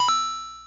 mario-coin.mp3